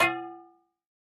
fo_fryingpan_clang_04_hpx
Frying pans clang together. Clang, Frying Pan